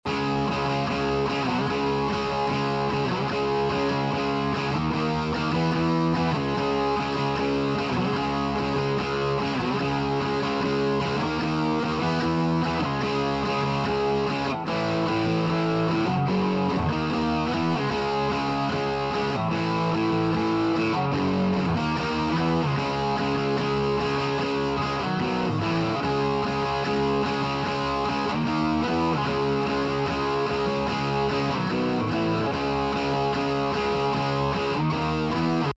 Gitarrenaufnahmen sind mit Resonanzgeräuschen übersäht
Hi! Ich habe das Problem, dass ich in meinen Gitarrenaufnahmen unglaublich hohe Resonanzgeräusche habe. Es wird über den Kemper aufgenommen und tritt eigentlich bei alles Profiles auf, auch mit verschiedenen Gitarren.